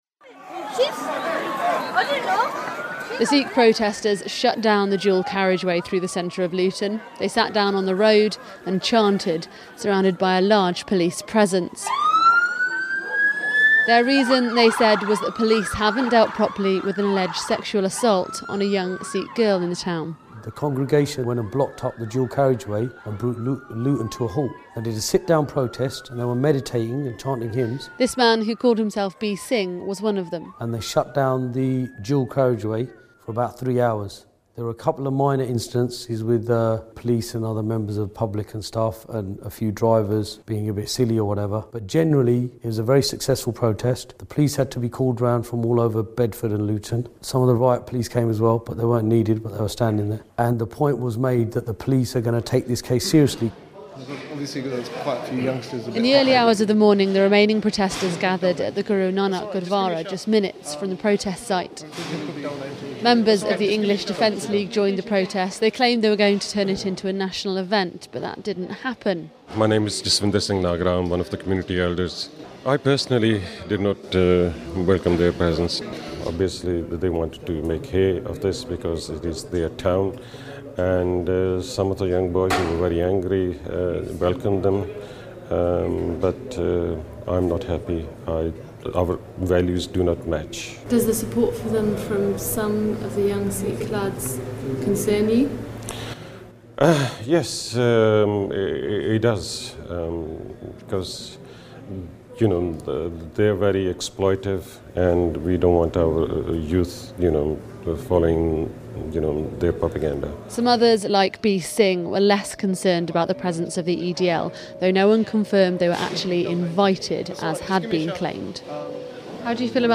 Police have said they are talking to community leaders following a protest by hundreds of Sikhs at a Luton police station. About 250 people gathered at Buxton Road over an allegation that a young Sikh woman had been sexually assaulted. Members of the English Defence League joined the protest.